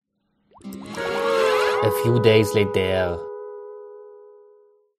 spongebob-transition-a-few-days-later.mp3